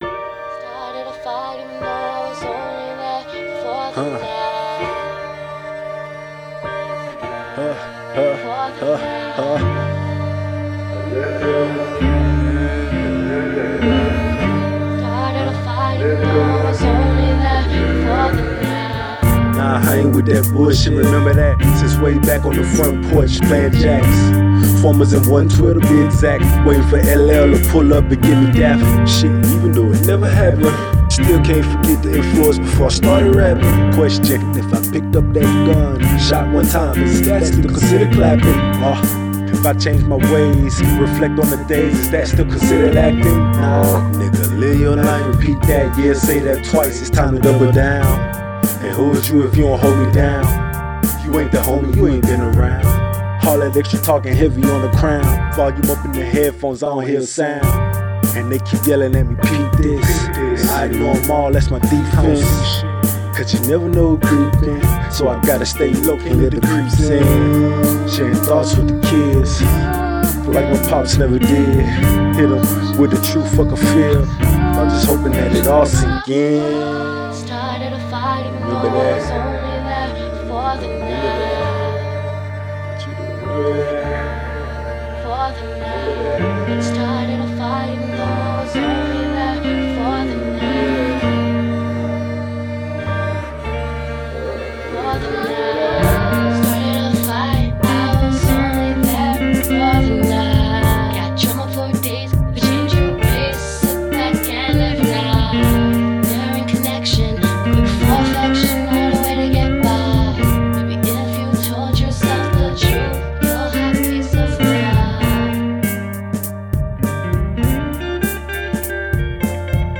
Each song has the vibes set for live performing